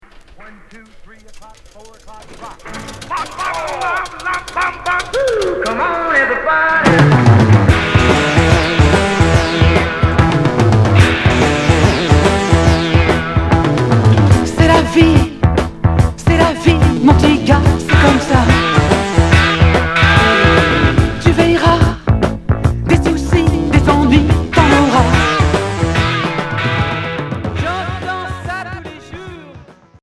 Glam